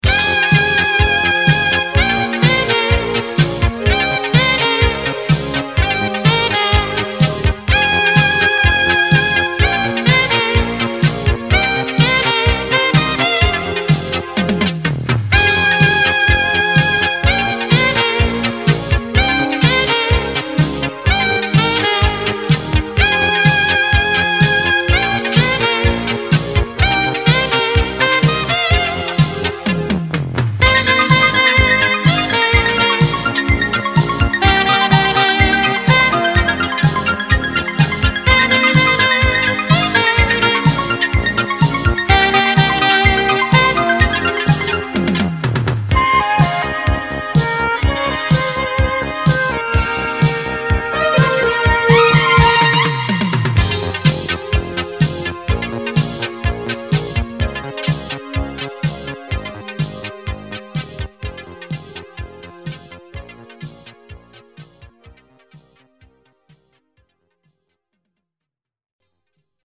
• Качество: 320, Stereo
саундтреки
без слов
инструментальные
Саксофон